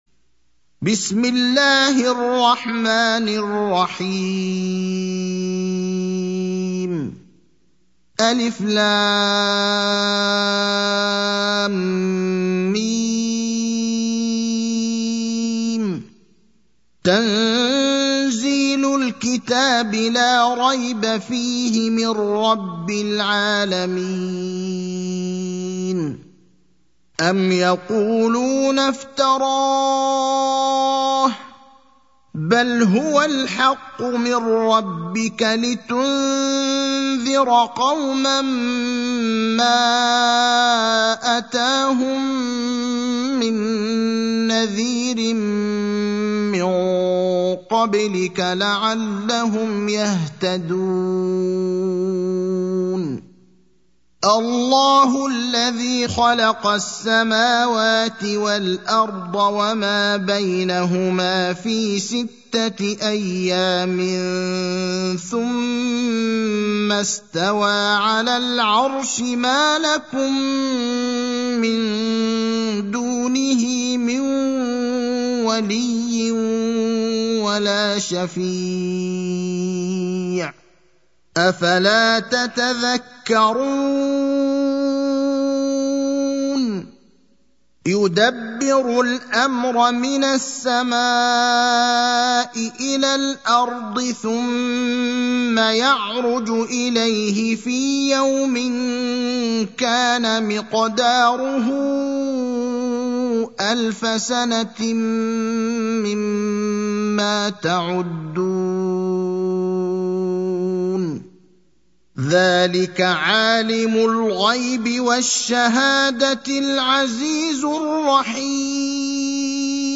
المكان: المسجد النبوي الشيخ: فضيلة الشيخ إبراهيم الأخضر فضيلة الشيخ إبراهيم الأخضر السجدة (32)